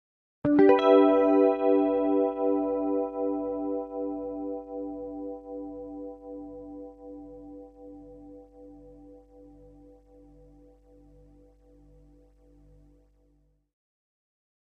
Electric Guitar Harmonics On 12th Fret With Chorus